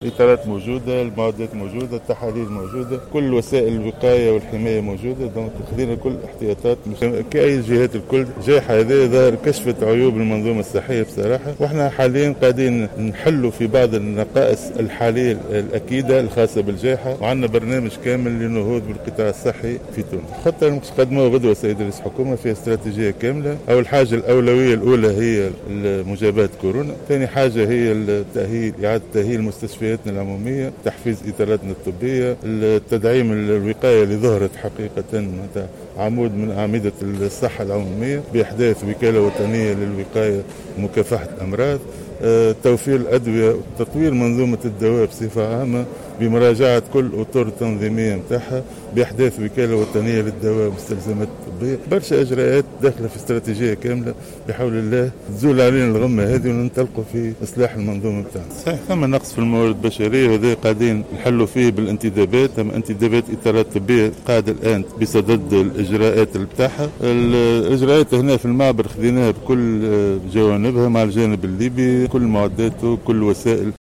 وأشار المهدي، في تصريح لمراسل الجوهرة أف أم، لدى زيارته للمعبر، اليوم السبت، إلى أنّه سيقعُ غدا عرضُ خطة وطنية لوزارة الصحة على رئيس الحكومة تضم استراتجية عمل الوزارة خلال الفترة القادمة من أجل النهوض بالقطاع .وتضم الخطة برنامجا لتحسين المستشفيات العمومية وتحفيز الإطارات الطبية وشبه الطبية وإحداث وكالة وطنية للوقاية ومكافحة الأمراض، ووكالة وطنية للدواء والمستلزمات الطبية.